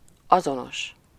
Ääntäminen
UK : IPA : /ˈseɪm/ US : IPA : /ˈseɪm/